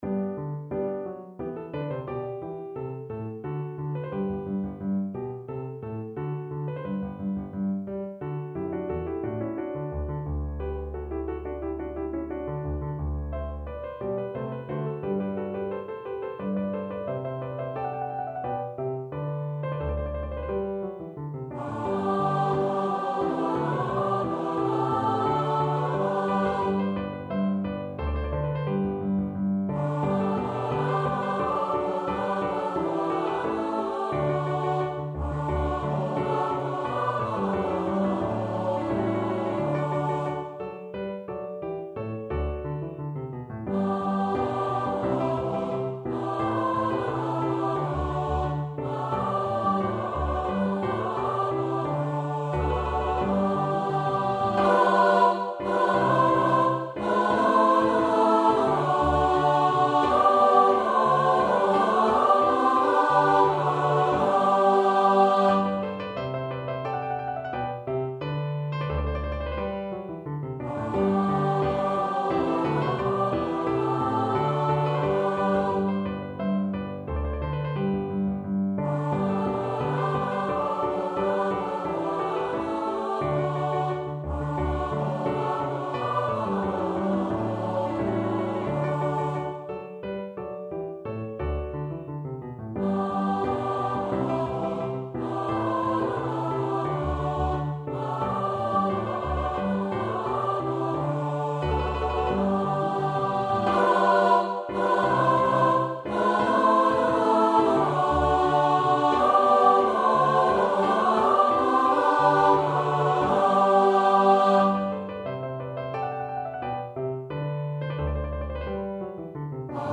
for SA+Men choir and Piano
The verses could be sung by a soloist or soloists.